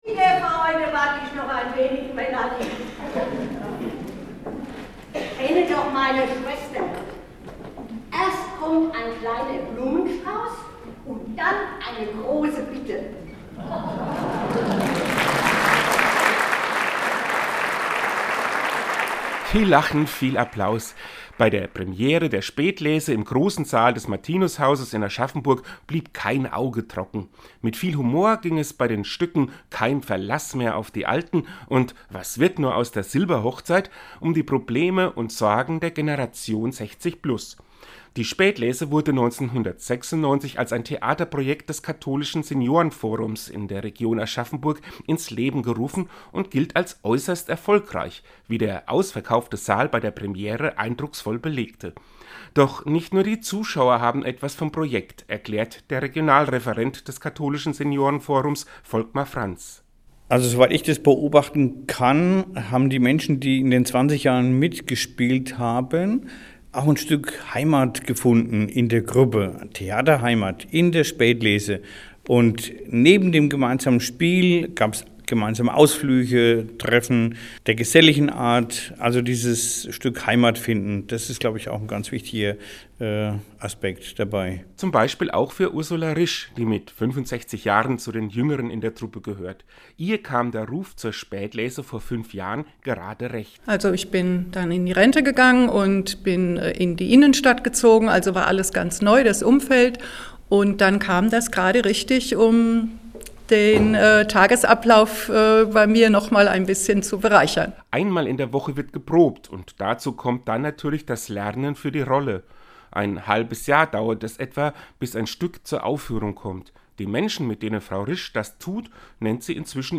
Seinen Radiobeitrag können Sie unten anhören.